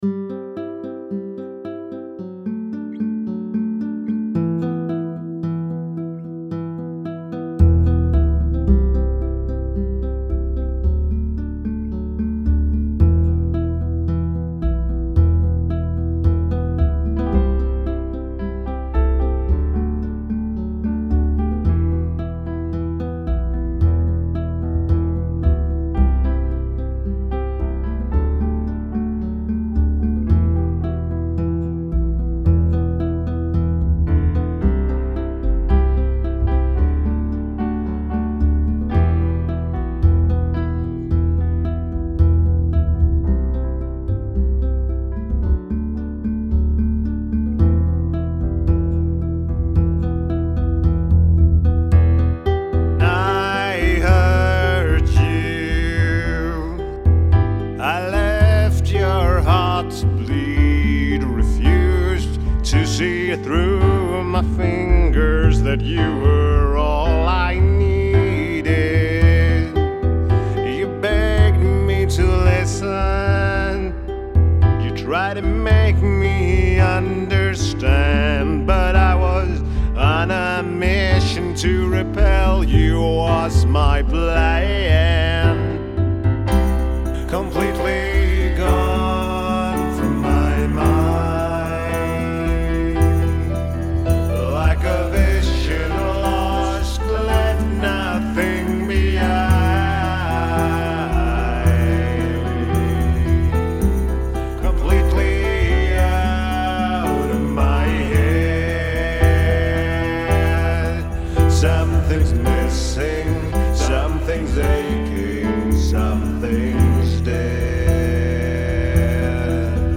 Denna versionen är något helt annat, mer avskalad.
Nu blev det en mididito eftersom jag lyckades ha av en sträng och saknar resever.
Snyggt ljud på midigitarren och fint pianospel!
leadsången i början som låter ansträngd och sur på några ställen.
Basen känns en aning mullrig och stark.
Det som kommer med körsångstämman samtidigt med tal efter 140 sekunder är riktigt nice!